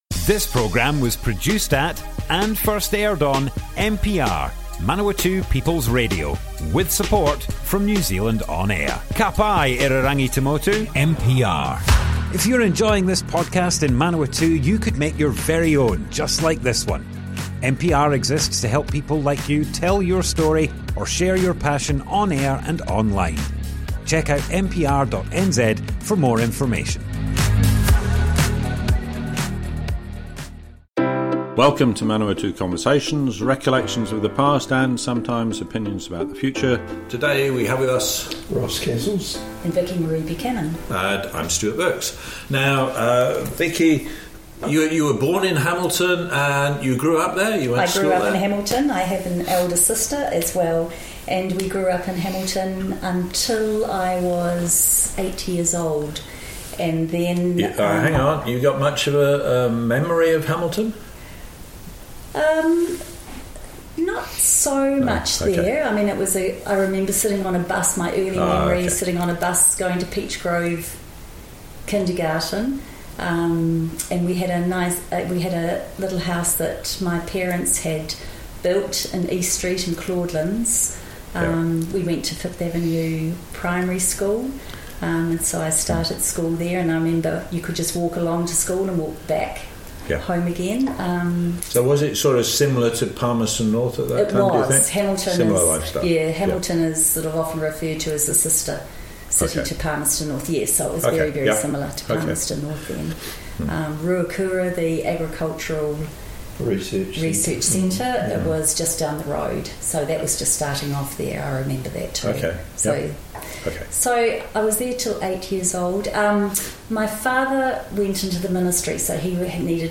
Manawatu Conversations More Info → Description Broadcast on Manawatu People's Radio, 11th June 2024.
oral history